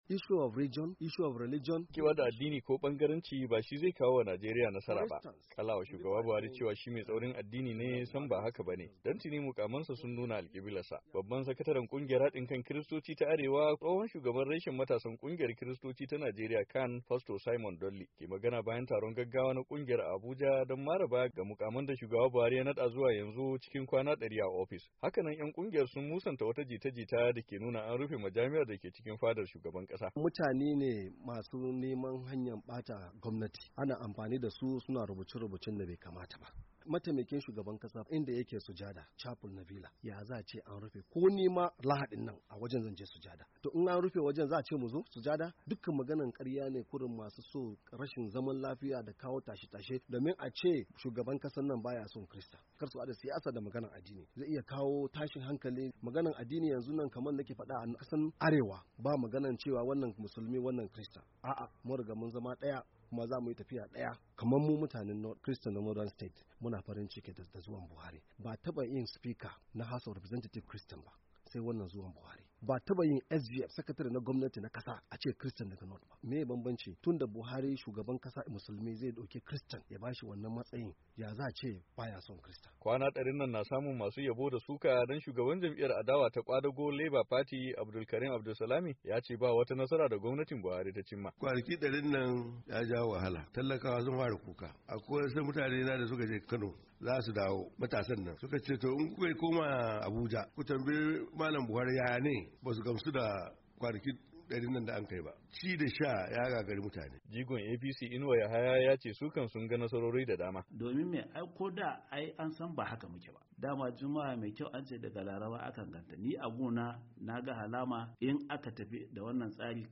Ga rahoton